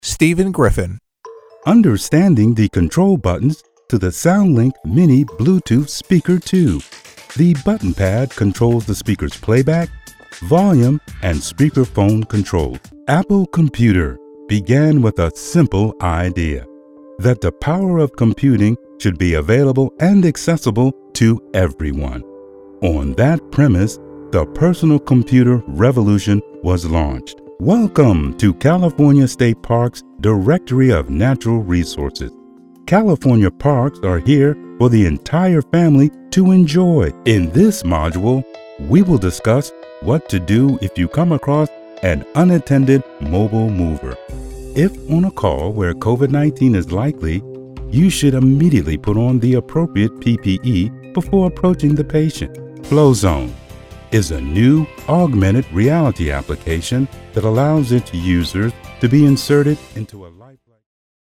smooth, calming, engaging Male Voice Over Talent
VOICE ACTOR DEMOS
Words that describe my voice are smooth, calming, engaging.